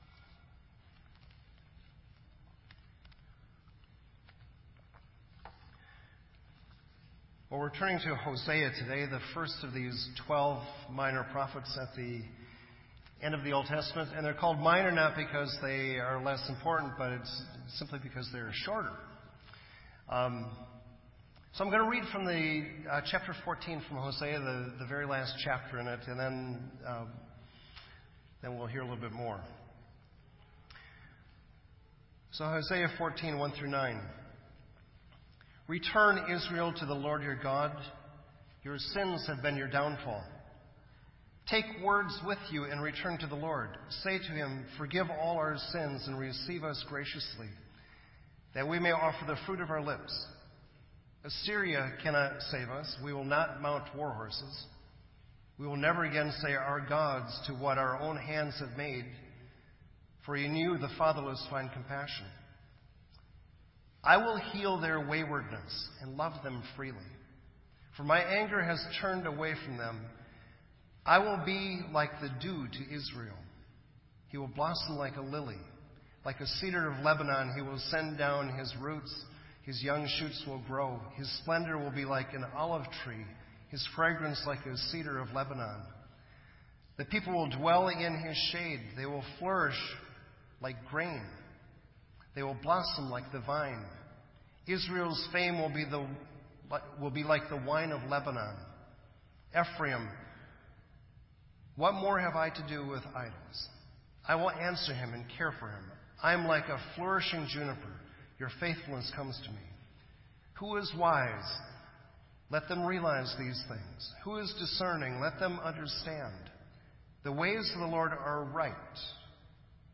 This entry was posted in Sermon Audio on June 12